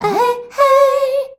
AHEHEY  A.wav